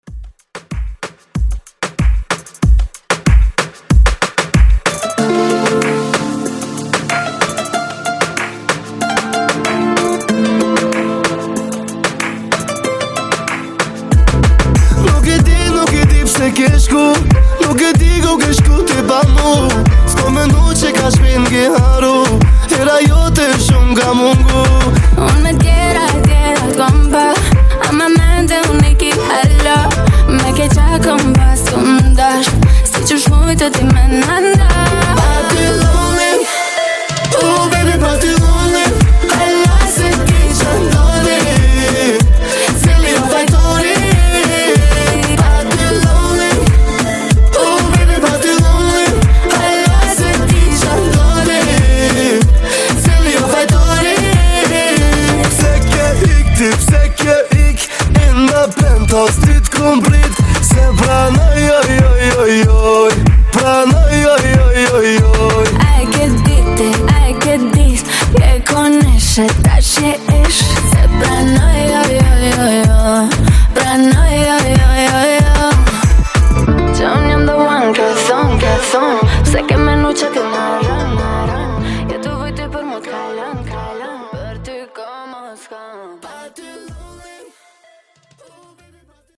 Genres: DANCE , GERMAN MUSIC , TOP40
Clean BPM: 122 Time